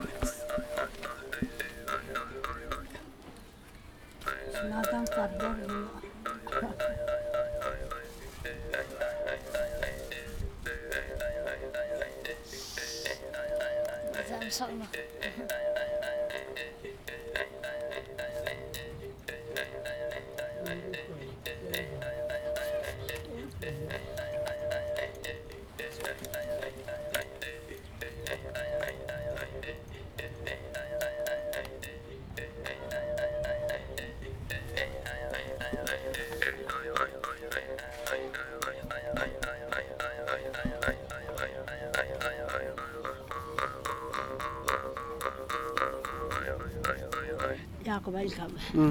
Performance of playing traditional instrument